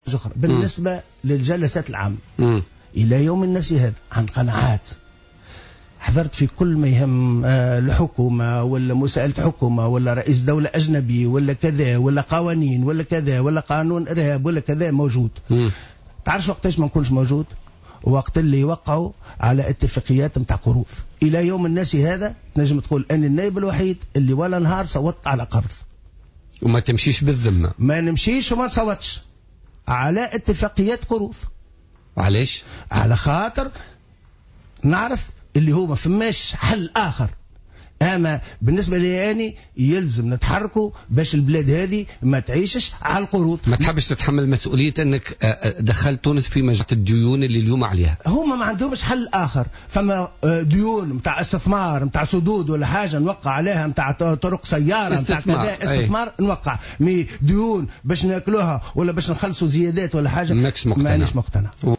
قال رضا شرف الدين النائب عن حزب نداء تونس وضيف برنامج بوليتكا لليوم الخميس 11 فيفري 2016 إنه لم يحضر ولم يصوت ولو مرة واحدة على اتفاقية قرض خلال الجلسات العامة ستجعل تونس في مديونية وذلك عن قناعة وفق قوله.